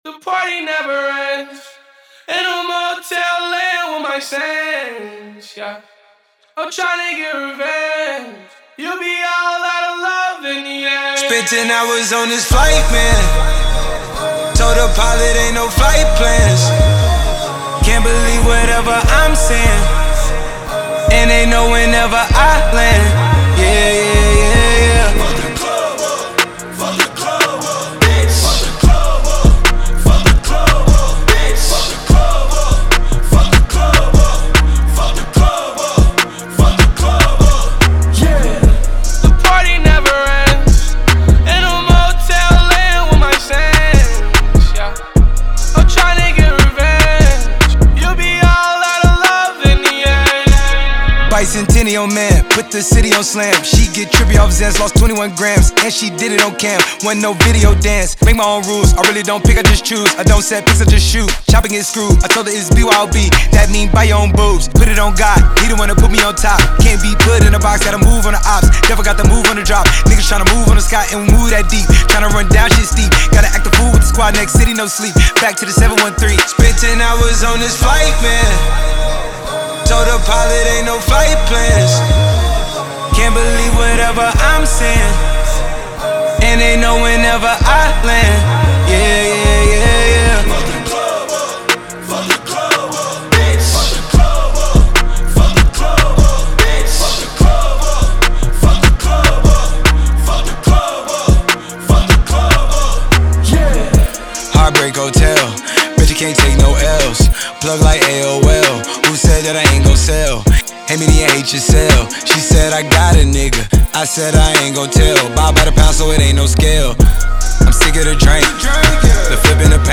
Hip-Hop Trap